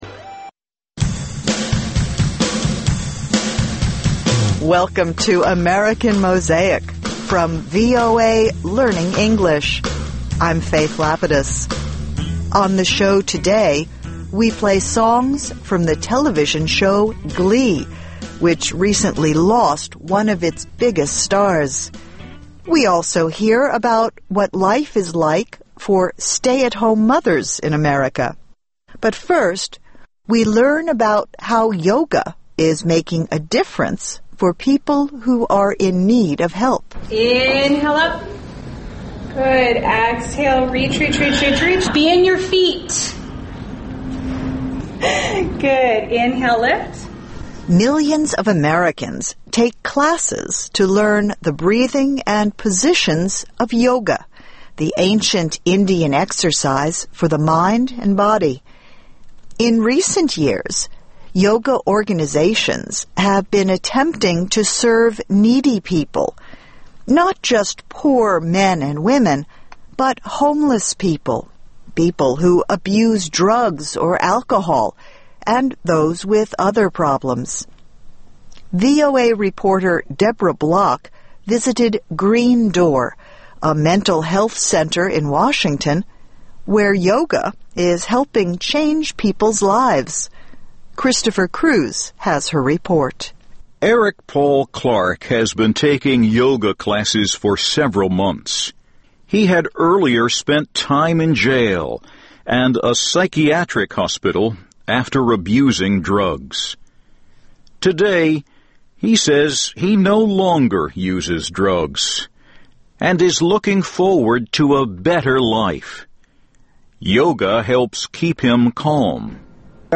ინგლისური ენის გაკვეთილები ("ამერიკის ხმის" გადაცემა)